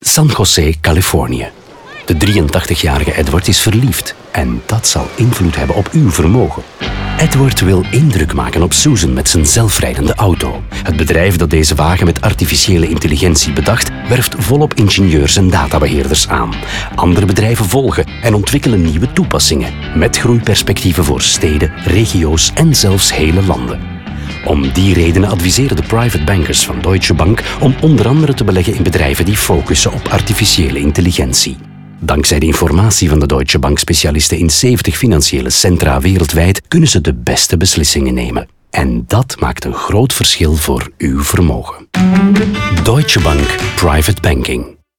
In de radiospots krijgen de verhalen van Rinku, Søren, Juan en Edward ruim de tijd, 45 seconden, om helemaal verteld te worden.